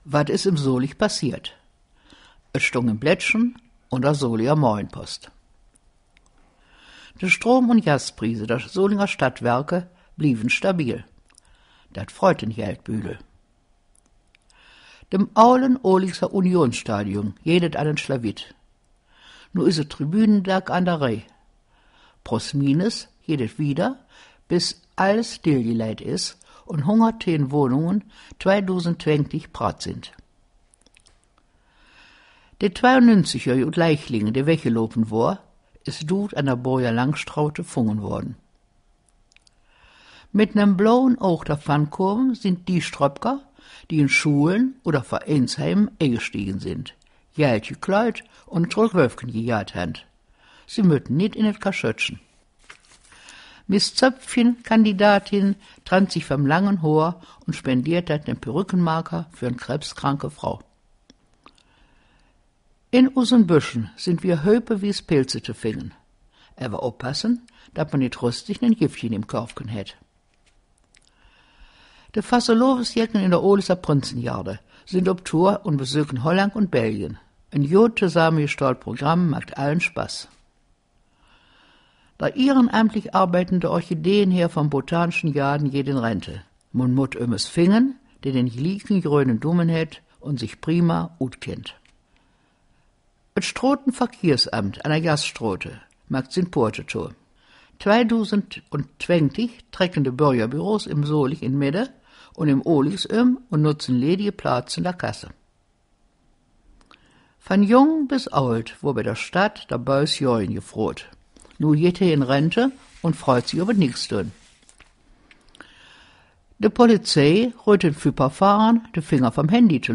Der Nachrichten-Rückblick auf die KW39
Solinger-Platt-News-18kw39.mp3